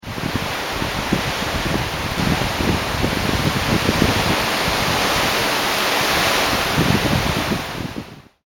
In the Nothofagus forest, Argentina
(Click to hear the wind.)
forest-wind-cr.mp3